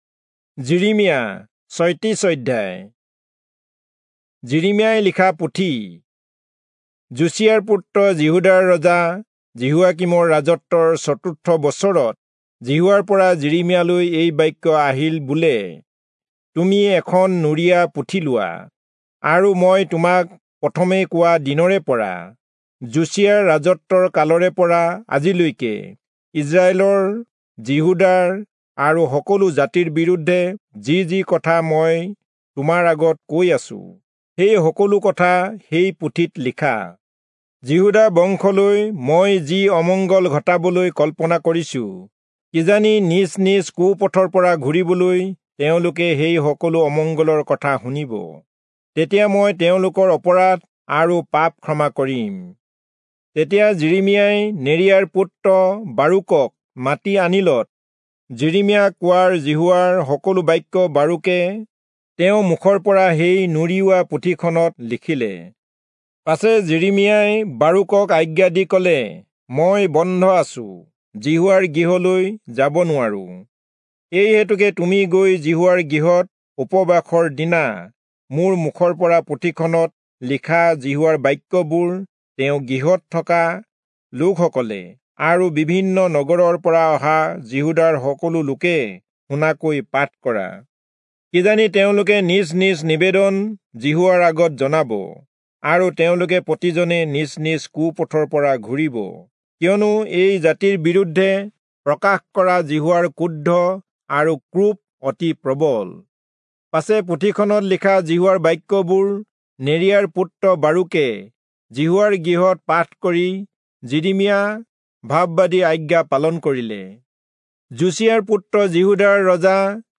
Assamese Audio Bible - Jeremiah 28 in Irvgu bible version